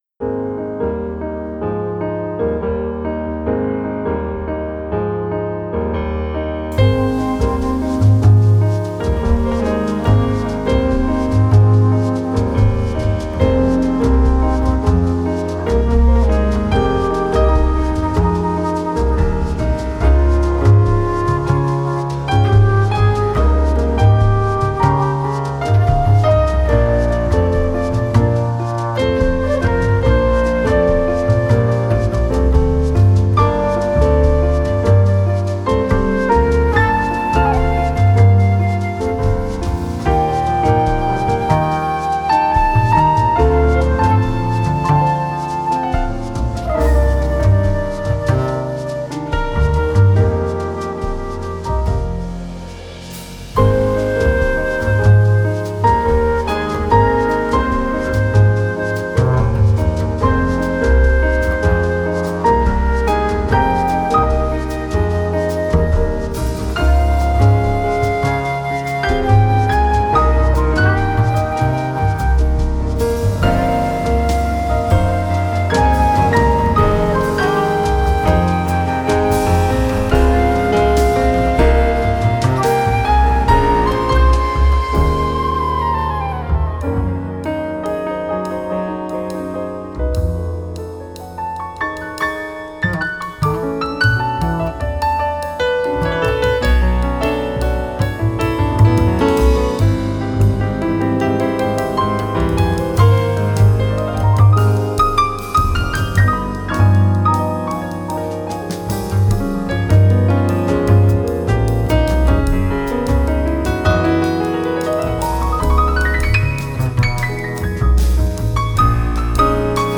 Genre: Vocal Jazz